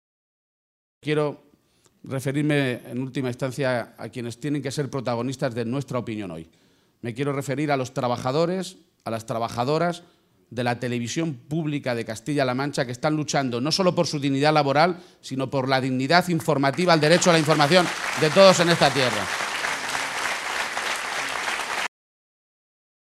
García-Page se pronunciaba de esta manera esta mañana en Azuqueca de Henares, primera de las tres localidades de la provincia de Guadalajara que ha visitado junto al secretario general del PSOE, Pedro Sánchez.